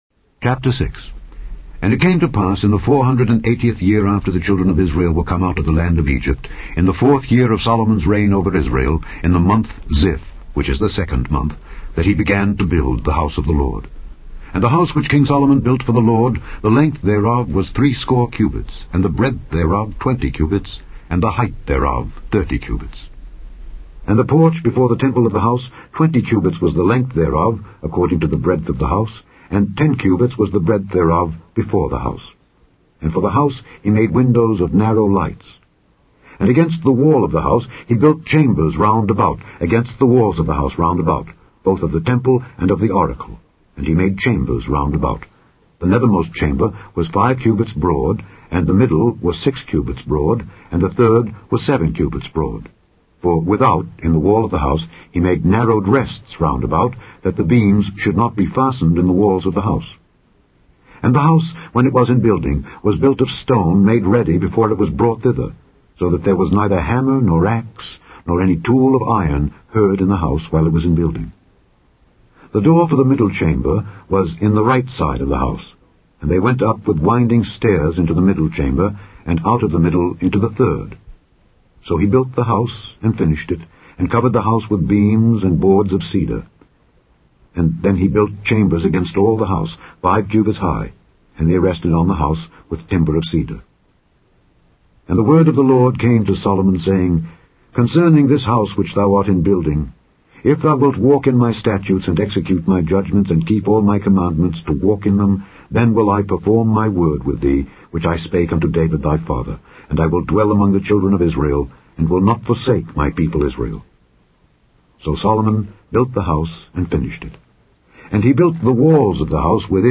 Conquerors Bible Class